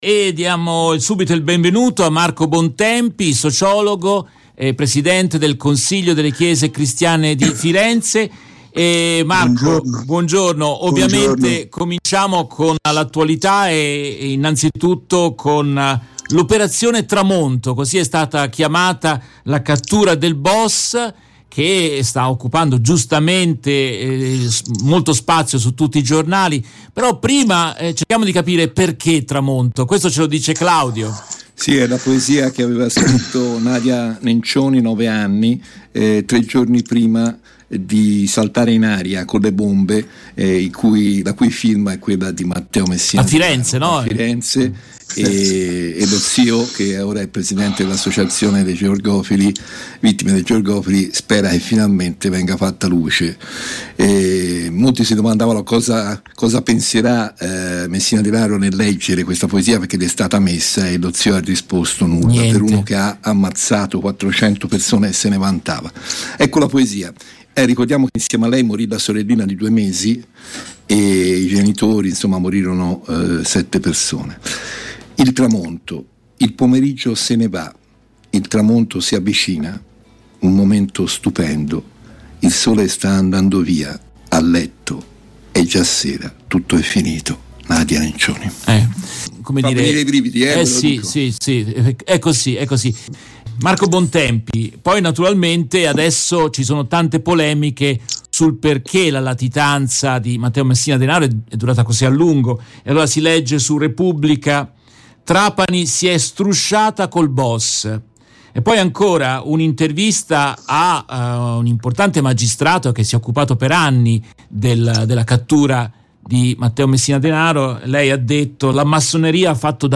In questa intervista tratta dalla diretta RVS del 18 gennaio 2023